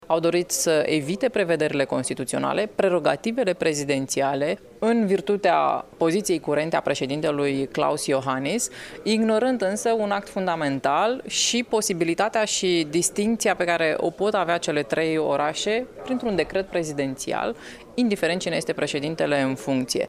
Afirmația a fost făcută, astăzi, la Iași, de vicepreședintele Senatului, parlamentarul liberal, Iulia Scântei.